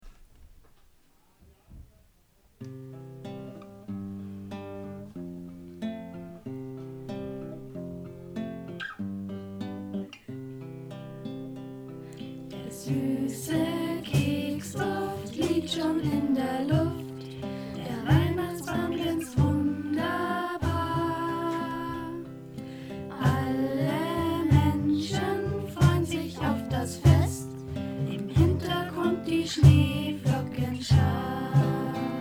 „Weihnachten ist für alle da“ – unser Klassen-Weihnachtslied
Gemeinsam haben die Kinder ein eigenes Weihnachtslied geschrieben und eingesungen – „Weihnachten ist für alle da“.